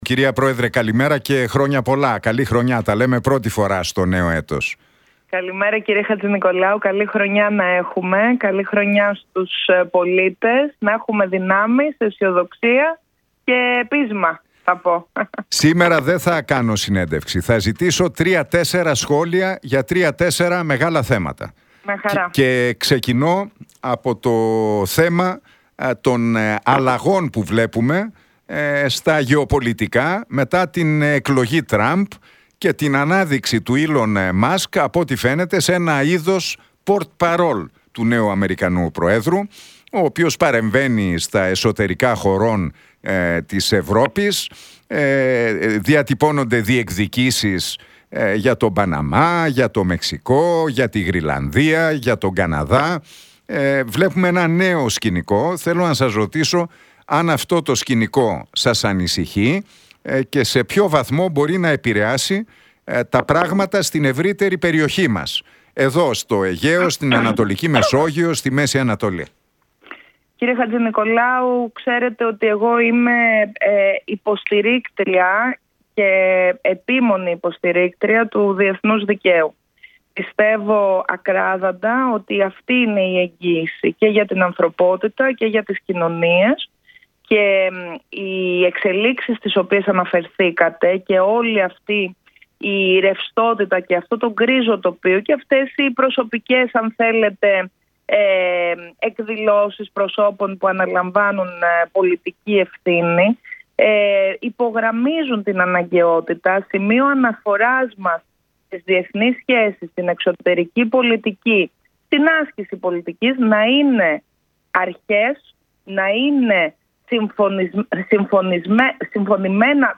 Για τα ελληνοτουρκικά, την ακρίβεια αλλά και την εκλογή προέδρου της Δημοκρατίας, μίλησε η πρόεδρος της Πλεύσης Ελευθερίας, Ζωή Κωνσταντοπούλου στον Νίκο Χατζηνικολάου από την συχνότητα του Realfm 97,8.